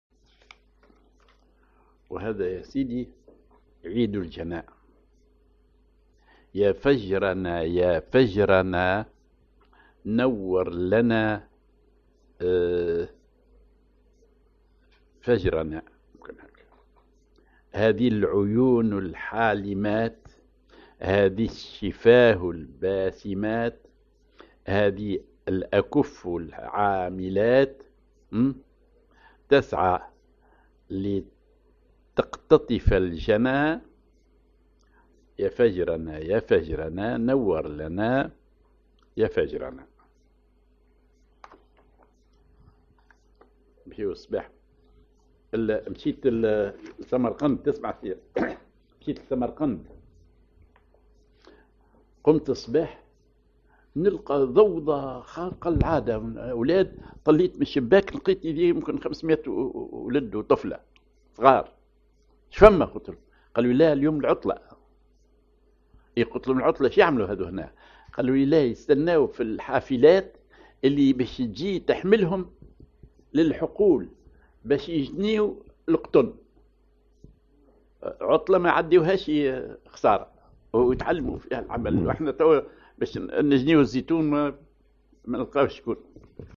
Maqam ar رصد ذيل
genre نشيد